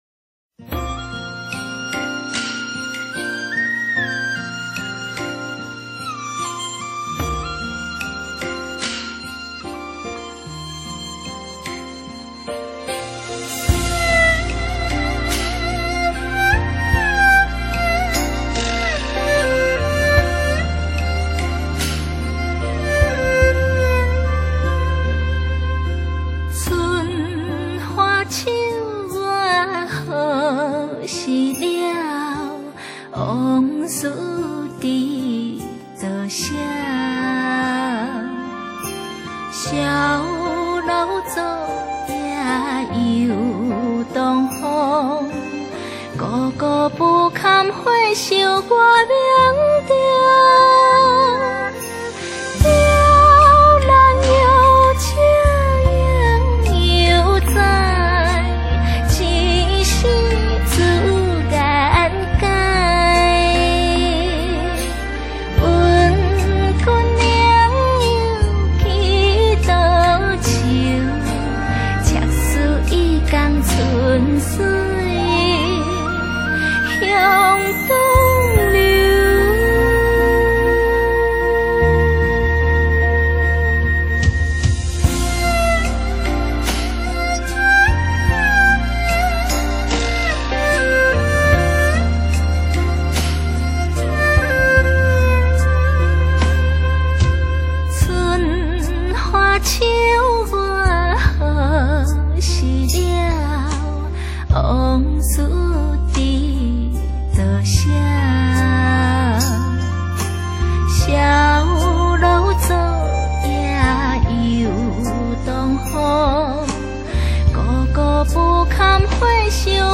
● 真假音絕美迴旋，加上極上悠揚古典編曲的新古典台語詩歌。